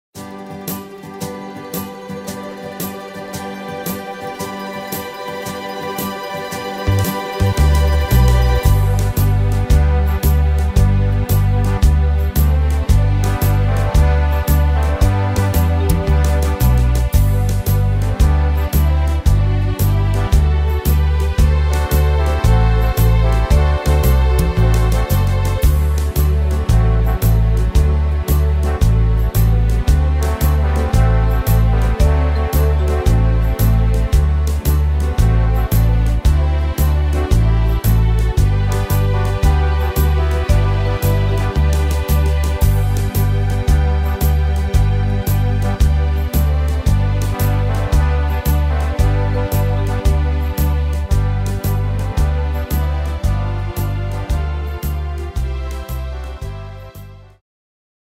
Tempo: 113 / Tonart: G-Dur